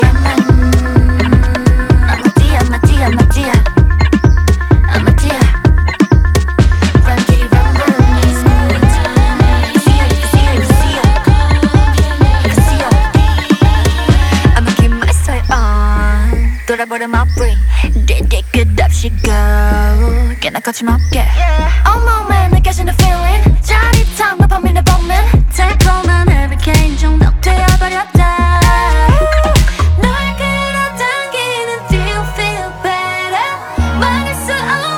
Dance Pop K-Pop
Жанр: Поп музыка / Танцевальные